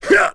Kaulah-Vox_Attack2.wav